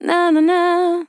NA NANA Sample
Categories: Vocals Tags: DISCO VIBES, dry, english, female, fill, NA, NANA, sample